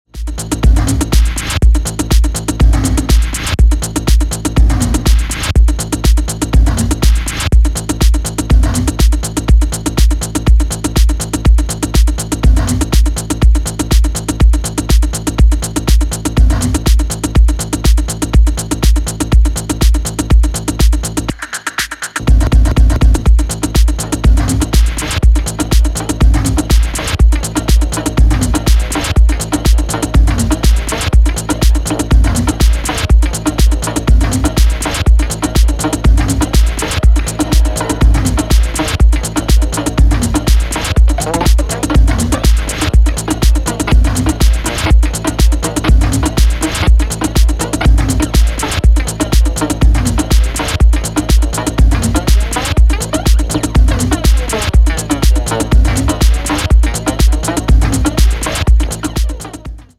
ファンキーなランニングベースでひたすら押しまくるミニマル・ディスコ